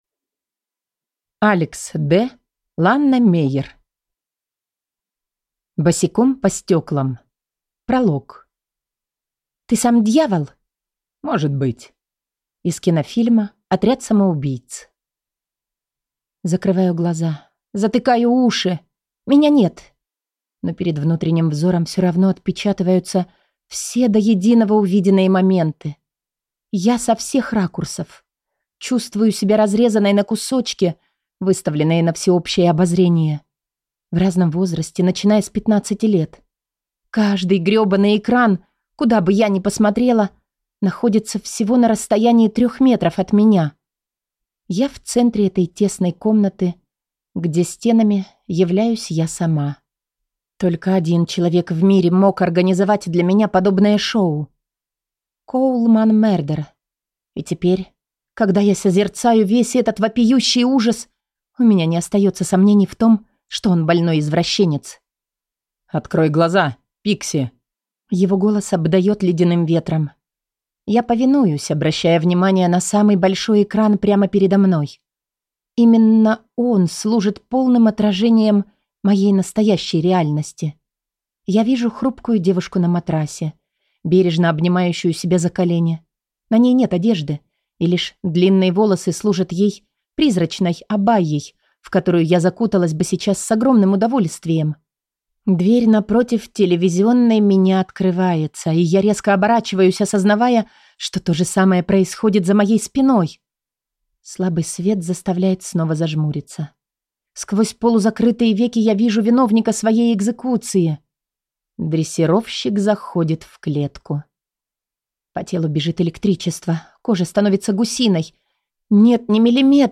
Аудиокнига Босиком по стеклам. Книга 1 | Библиотека аудиокниг